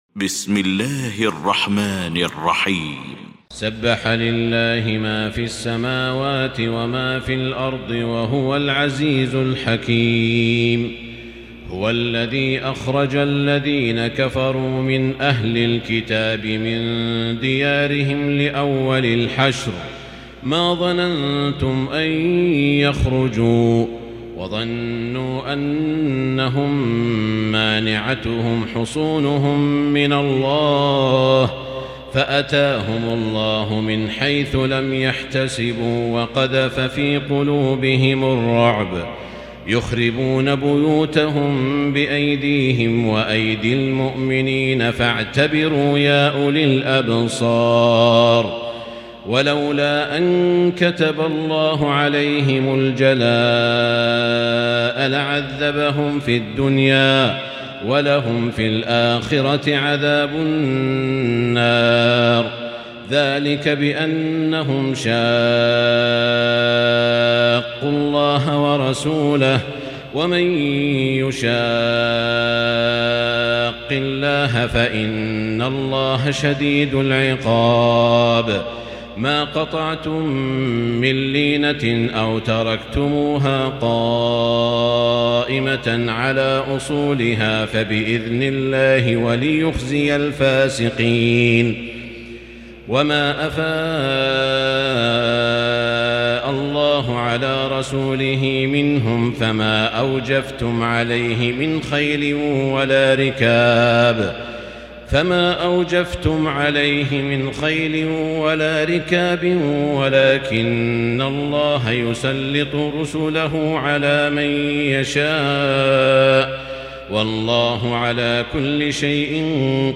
المكان: المسجد الحرام الشيخ: سعود الشريم سعود الشريم الحشر